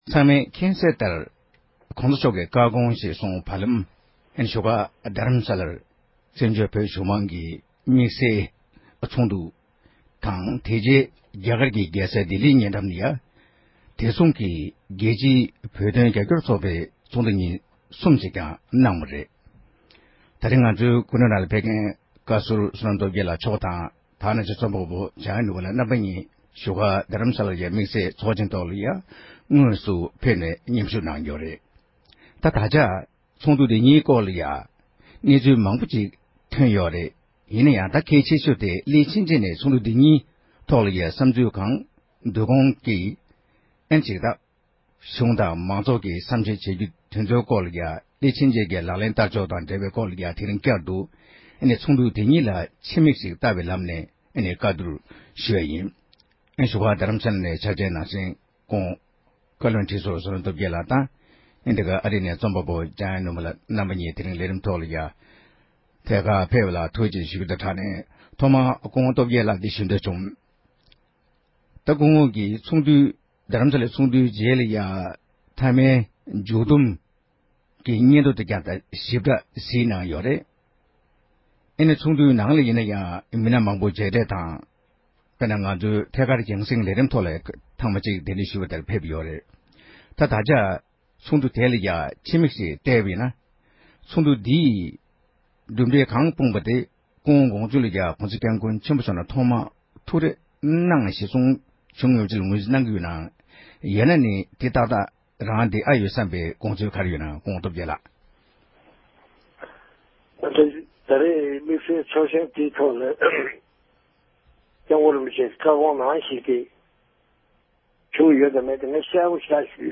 བཙན་བྱོལ་བོད་གཞུང་མང་གི་དམིགས་བསལ་ཚོགས་ཆེན་གྱི་གྲུབ་འབྲས་དང་འབྲེལ་ཡོད་གནས་ཚུལ་སྐོར་གྱི་བགྲོ་གླེང༌།